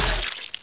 splat2.mp3